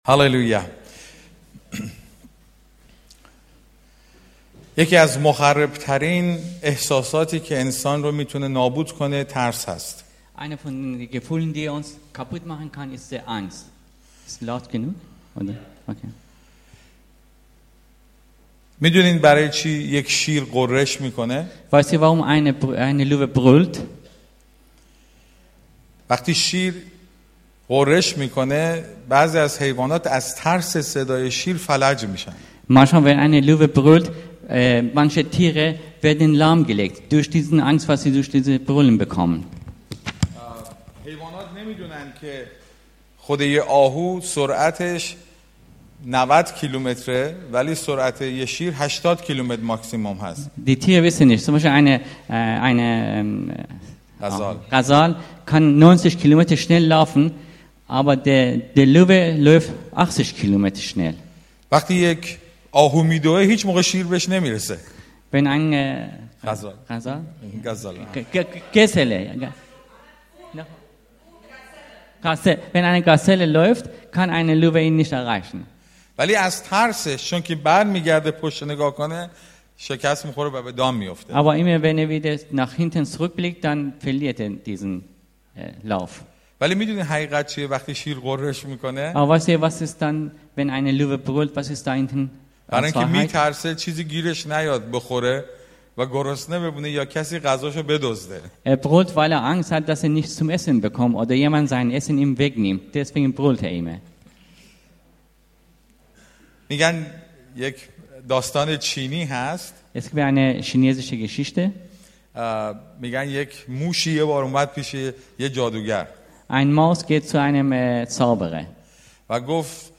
In farsi mit Übersetzung auf deutsch.